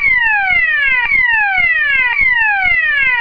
AVISADOR MIXTO - 49 SONIDOS - LUZ DESTELLANTE DE LED
Serie: EMERGENCIA- EVACUACION
49 sonidos seleccionables con volumen ajustable